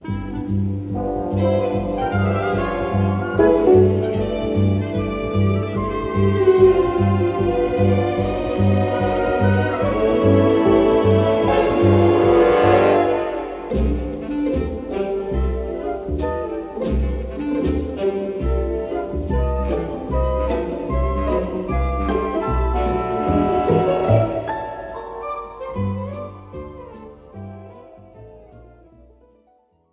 instrumental backing track cover
• Without Backing Vocals
• No Fade